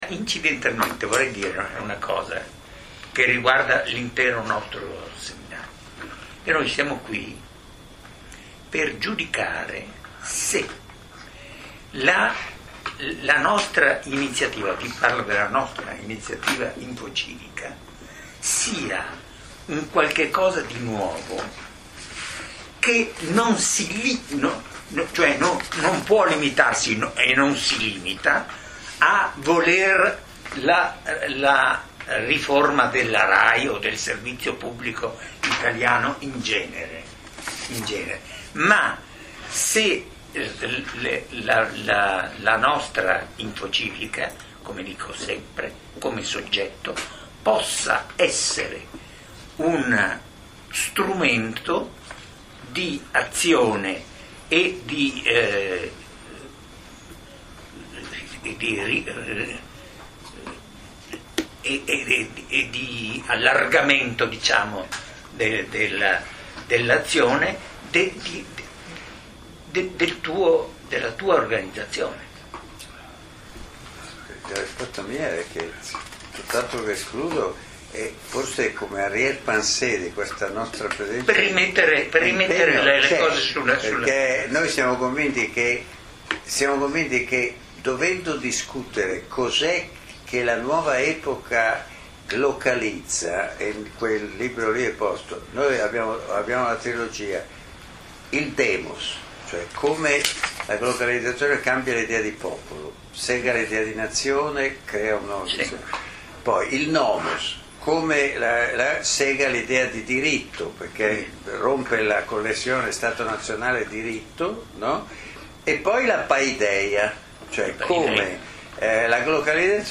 Seminario di Infocivica in collaborazione con Globus et Locus
Milano - 15 gennaio 2009